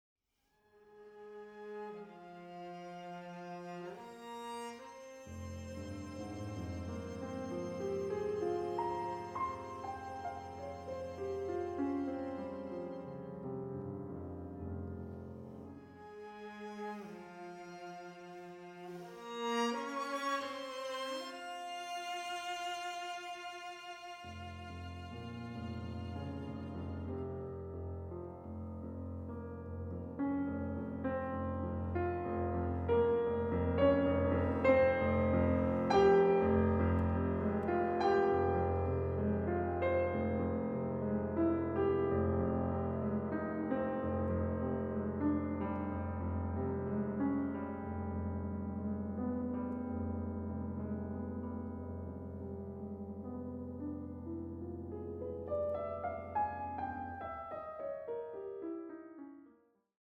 III. Adagio, dolce ed espressivo